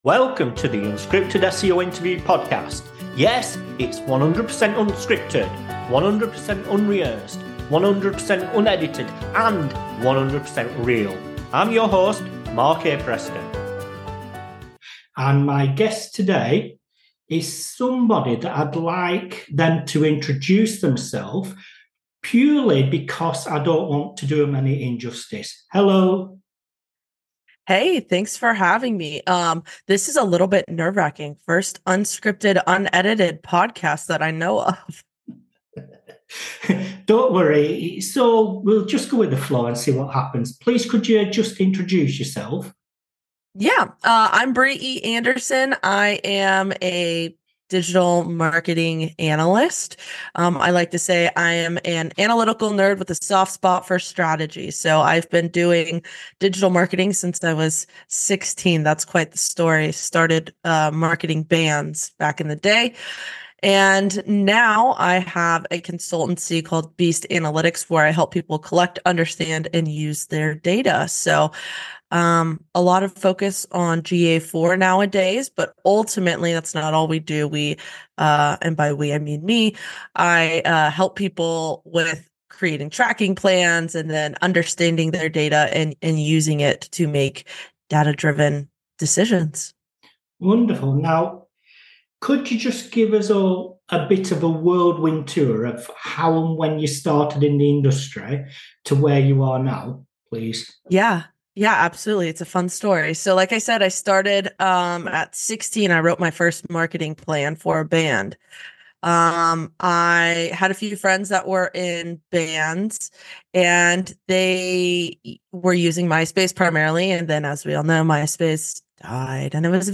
The Unscripted SEO Interview Podcast